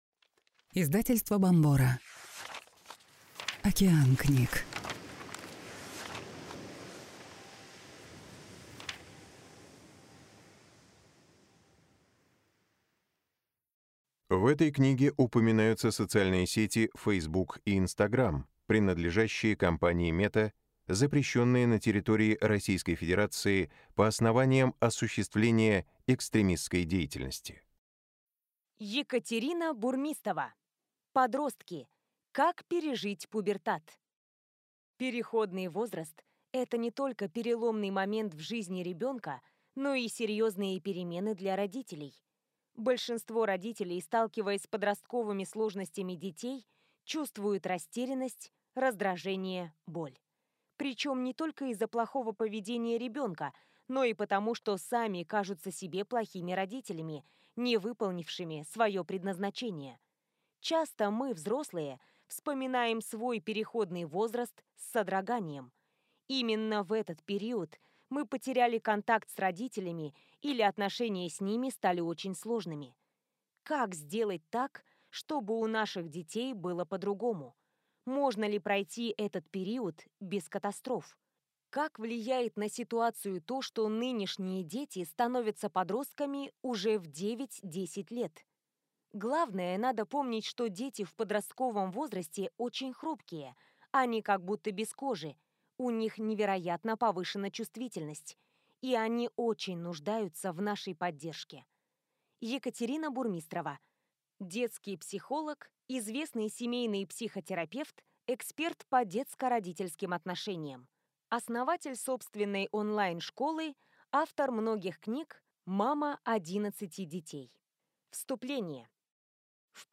Аудиокнига Подростки. Как пережить пубертат | Библиотека аудиокниг